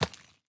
immersive-sounds / sound / footsteps / resources / ore-04.ogg